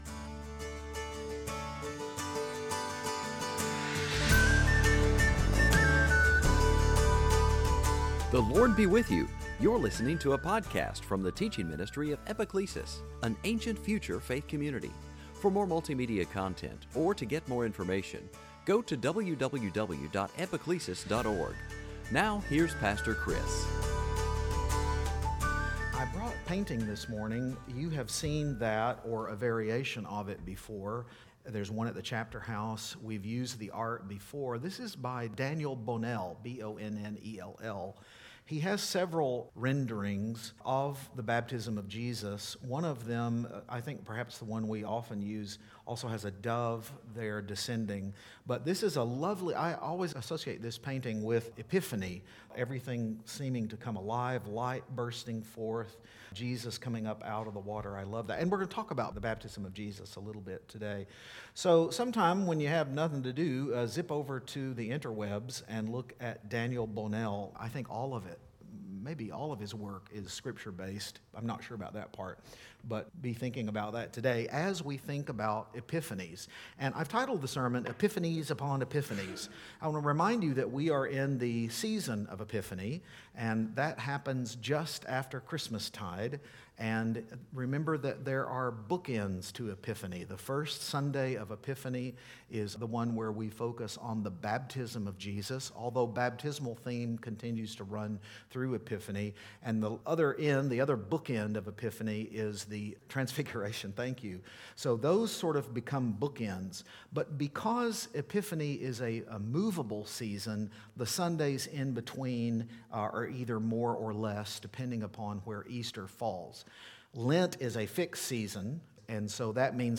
Service Type: Epiphany